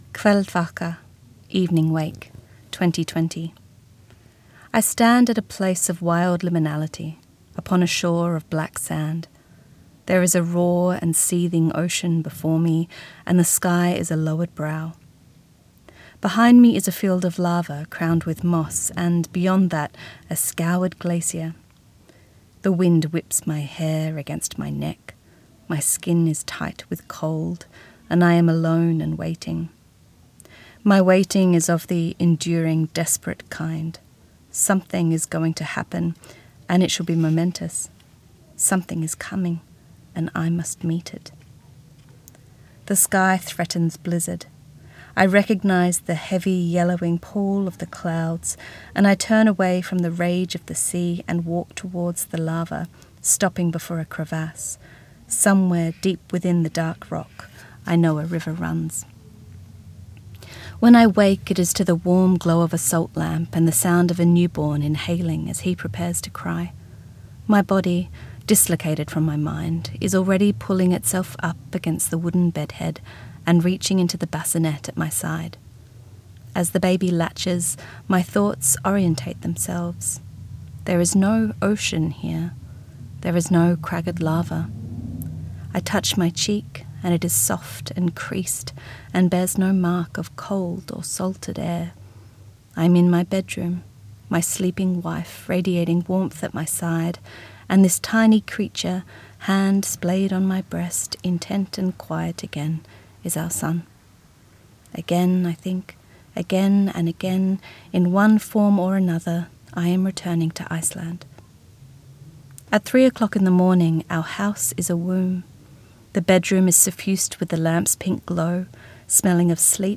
Recorded at Bellingen Readers and Writers Festival 2025
Hannah-Kent-read.mp3